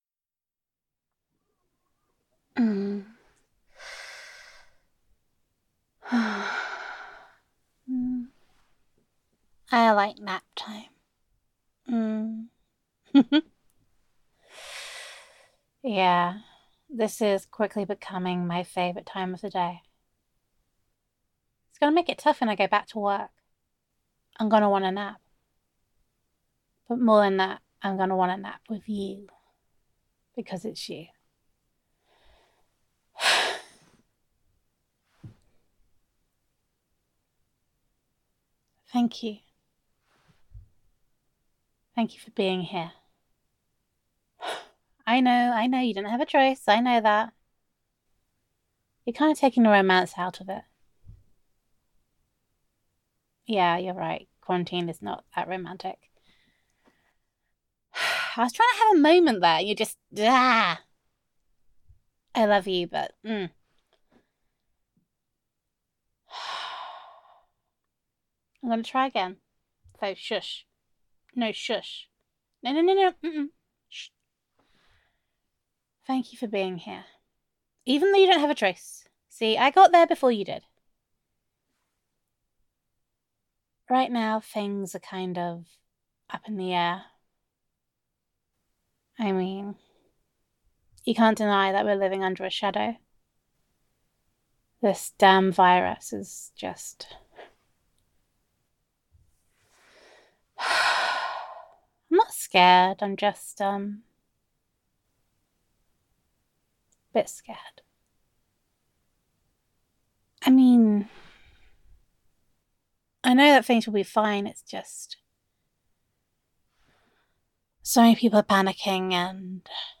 [F4A] Day Two - Nap Time Is the Best Time [Girlfriend Roleplay][Self Quarantine][Domestic Bliss][Gender Neutral][Self-Quarantine With Honey]